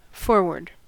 Ääntäminen
US : IPA : [ˈfɔɹˌwɝːd] UK : IPA : [ˈfɔːˌwɜːd]